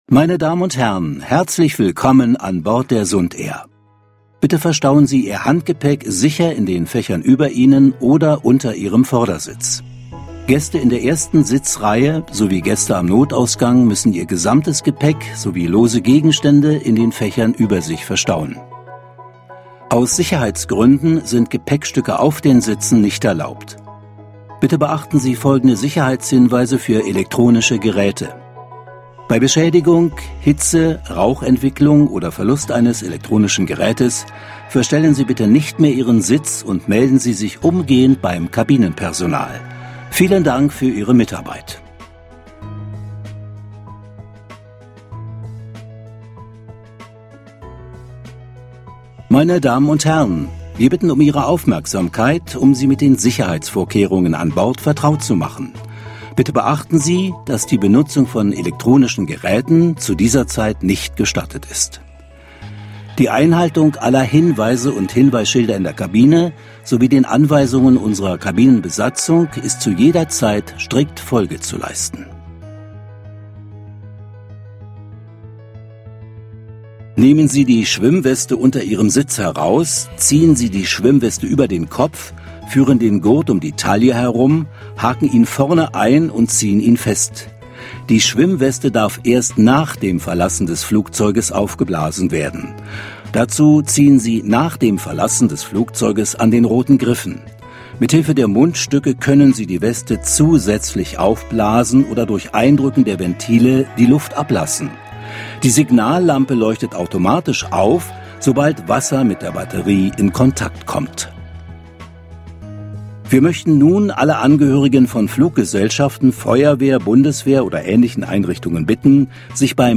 Sprecher, Werbesprecher